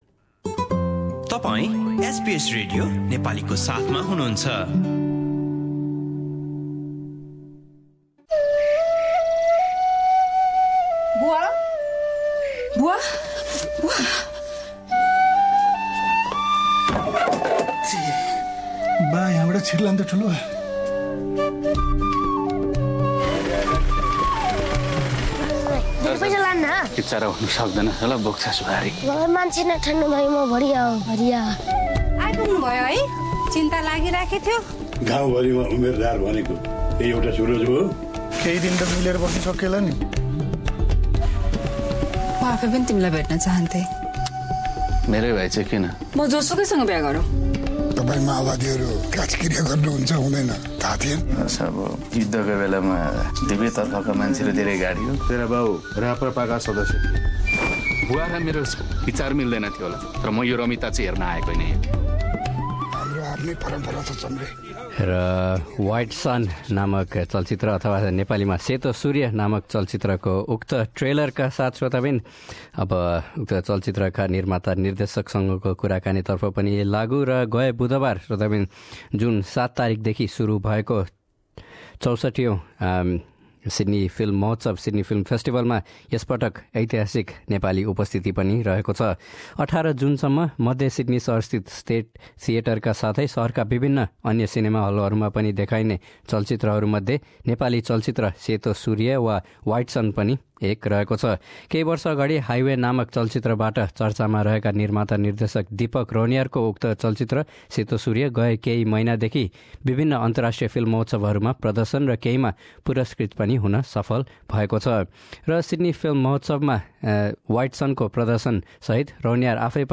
speaking to SBS Nepali in Sydney